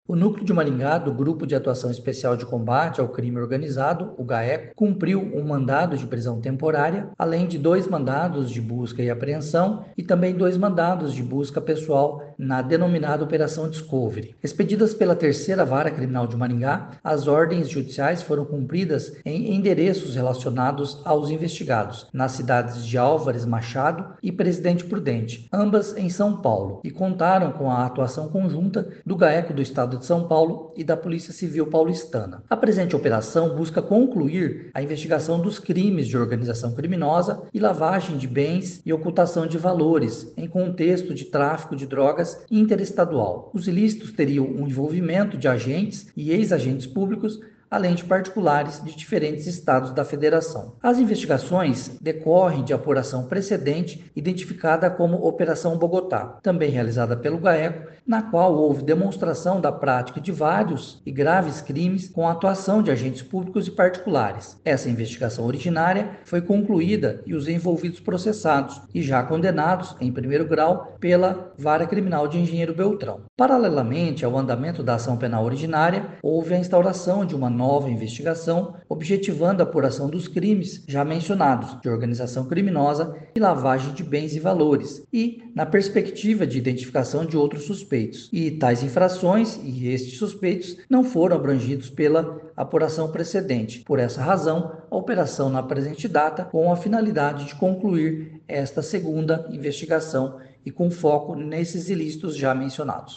Ouça o que diz o promotor de Justiça Marcelo Alessandro da Silva Gobbato: